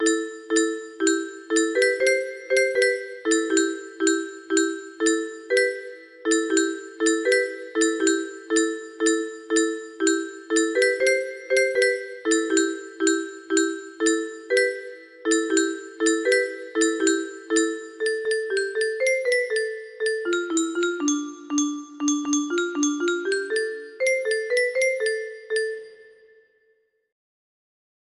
Gaudete music box melody